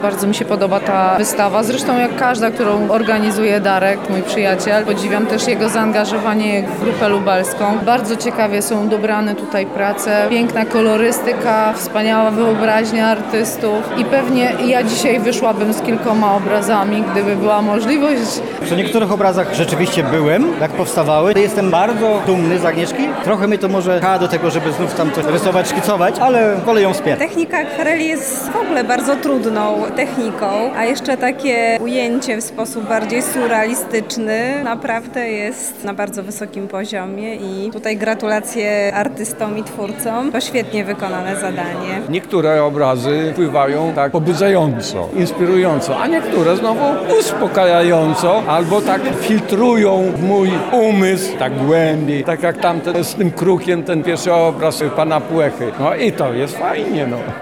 Zapytaliśmy także oglądających o wrażenia, jakie wywołały zaprezentowane dzieła:
sonda